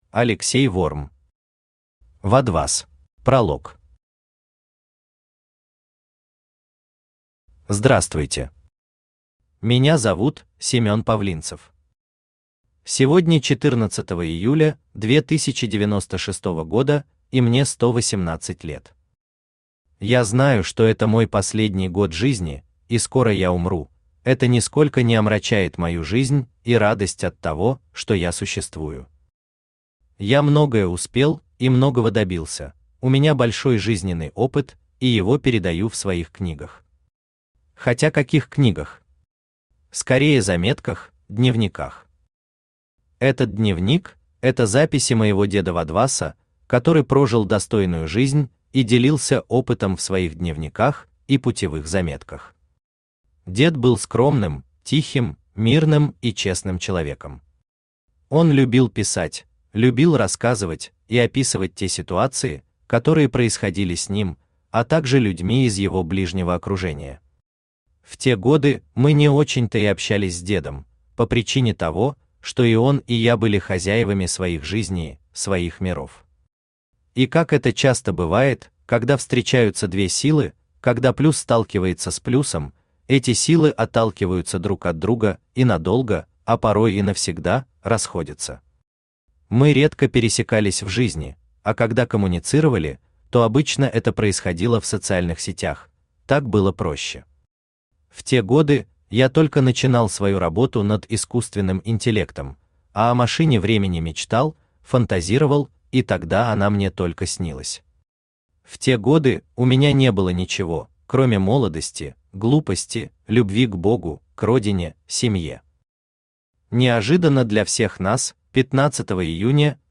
Аудиокнига Вадвас | Библиотека аудиокниг
Aудиокнига Вадвас Автор Алексей Ворм Читает аудиокнигу Авточтец ЛитРес.